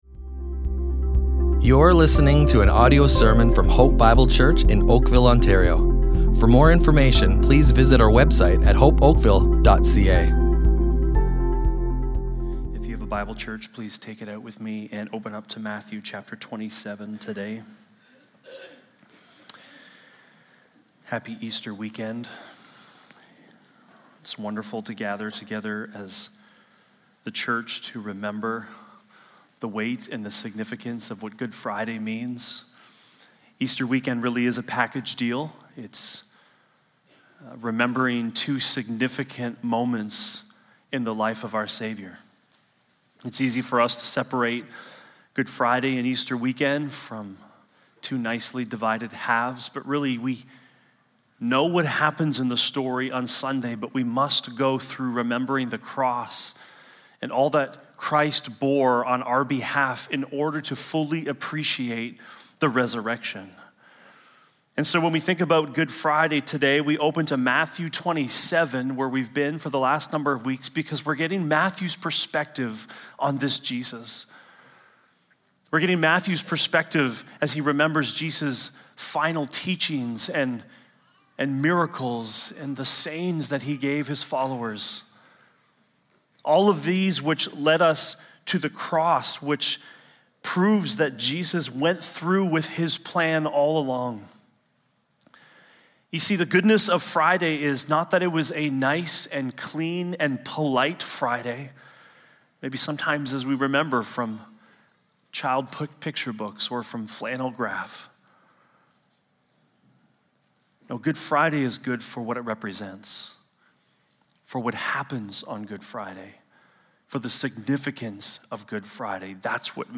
Hope Bible Church Oakville Audio Sermons